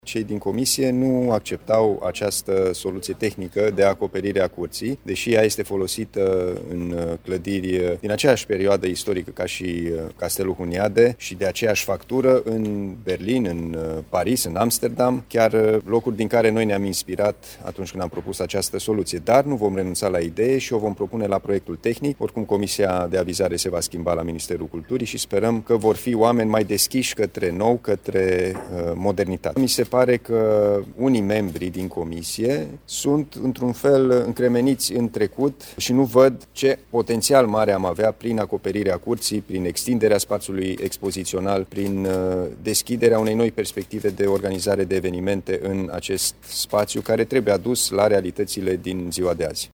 Tema va fi reluată de autoritățile județene în discuțiile cu Institutului Național al Patrimoniului, spune președintele CJ Timiș, Alin Nica: